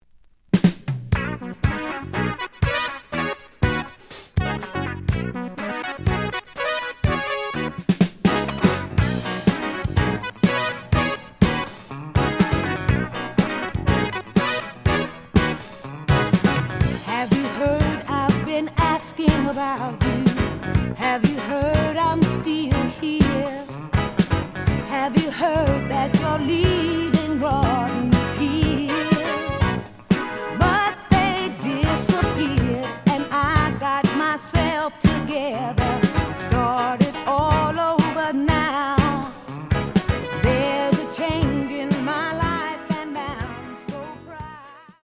試聴は実際のレコードから録音しています。
The audio sample is recorded from the actual item.
●Format: 7 inch
●Genre: Disco